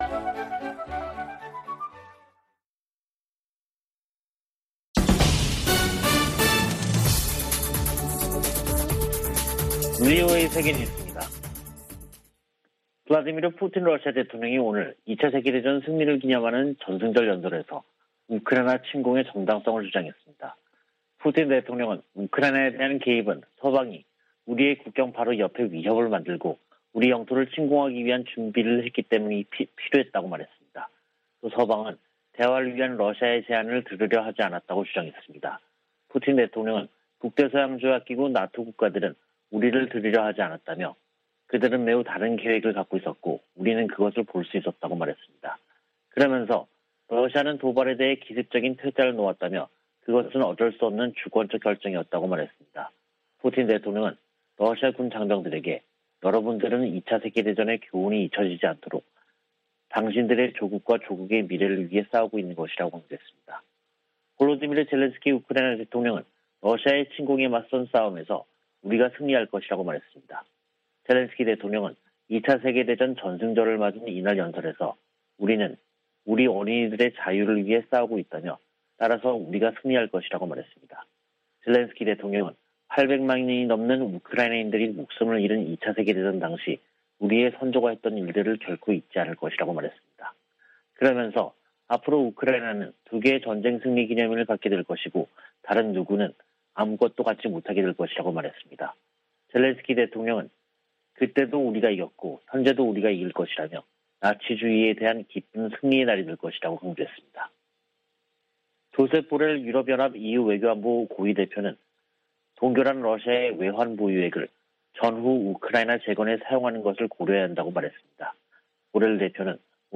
VOA 한국어 간판 뉴스 프로그램 '뉴스 투데이', 2022년 5월 9일 3부 방송입니다. 북한이 7일 오후 함경남도 신포 해상에서 잠수함발사 탄도미사일(SLBM)을 발사했습니다. 미 국무부는 미사일 도발을 이어가는 북한을 규탄하고 한・일 양국에 대한 방어 공약을 재확인했습니다. 미 하원에서 오는 12일 올해 첫 대북 정책 청문회가 개최될 예정입니다.